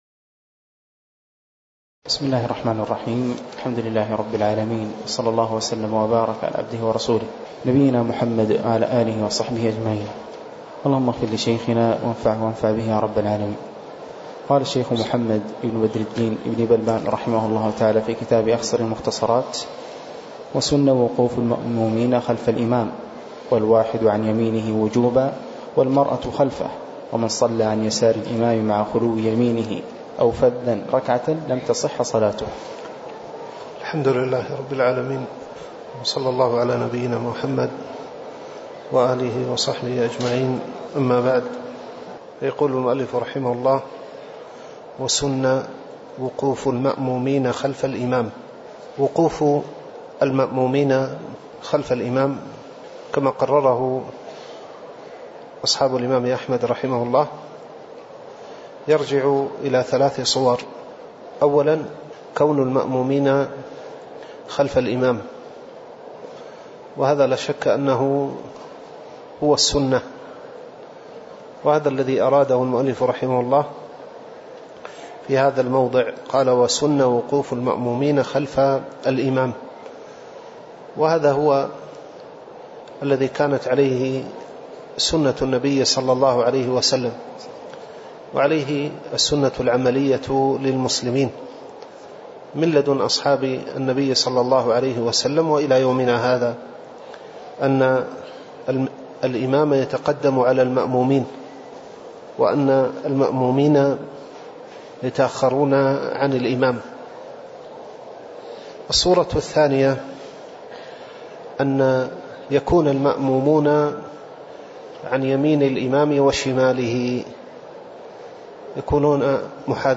تاريخ النشر ٤ رجب ١٤٣٩ هـ المكان: المسجد النبوي الشيخ